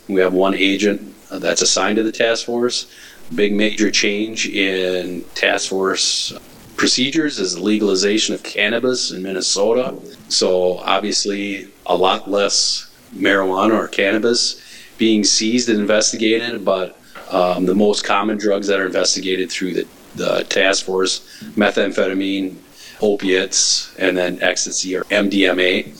Hutchinson Police Services gave the City Council its 2024 Year End Report Tuesday evening.
Chief Gifferson told the council drug enforcement has changed recently: